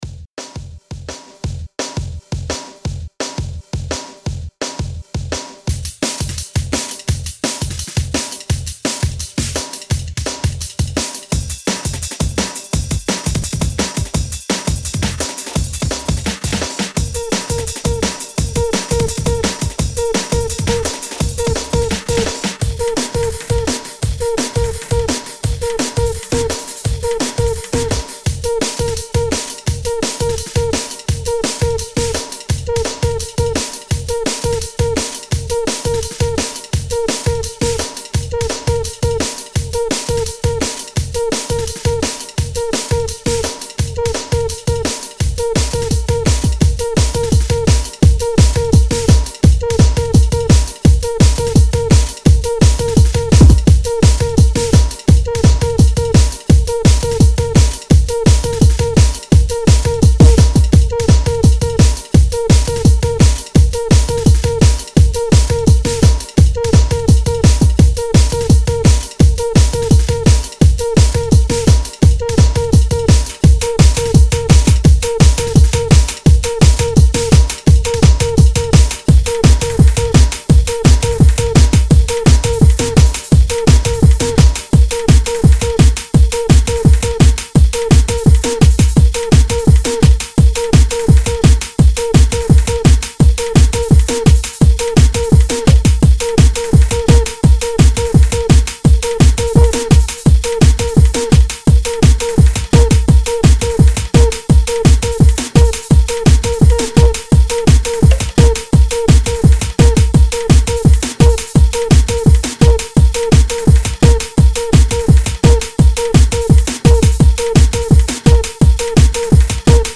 04/02/2013 Etiquetes: freetekno Descàrregues i reproduccions